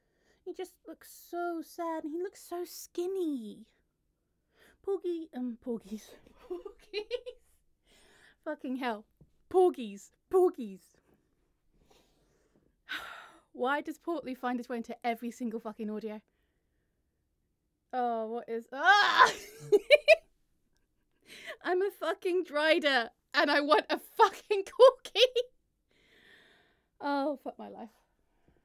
Can you hear that I am ill??